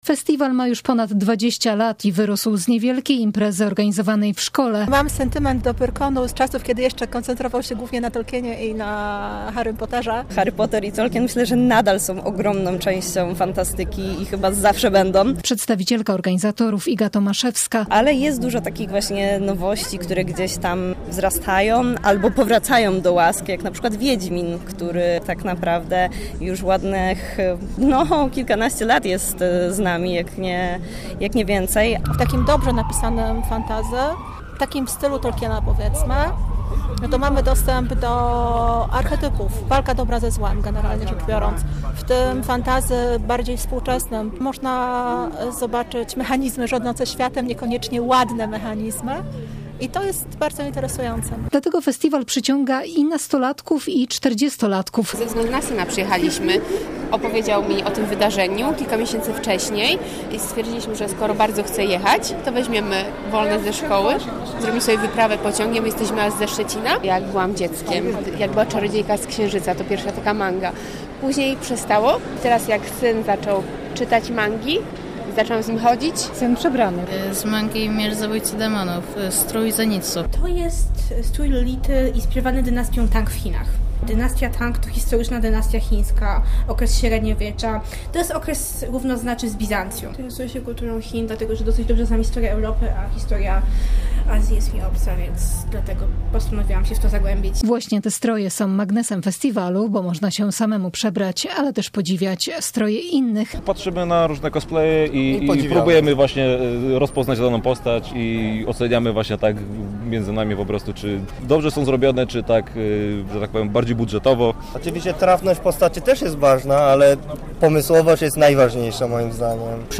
- mówili uczestnicy.